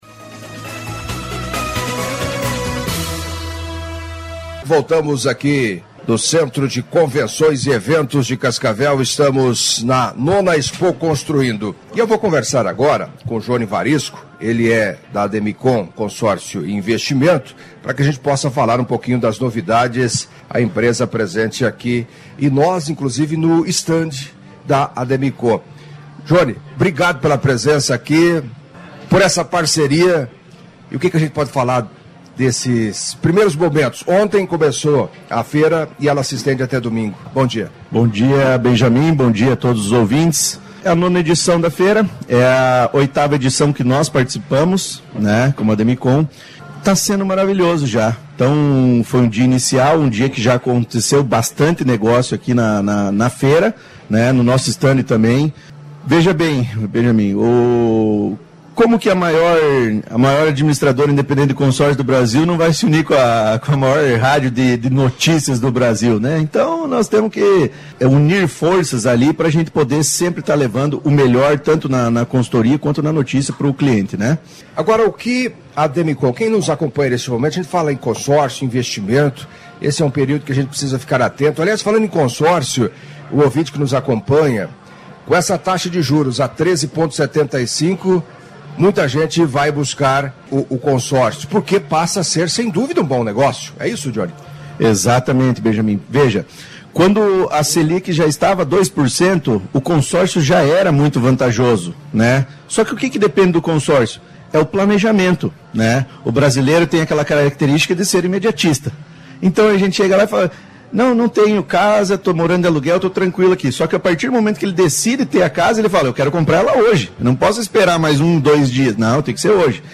Em entrevista à CBN nesta sexta-feira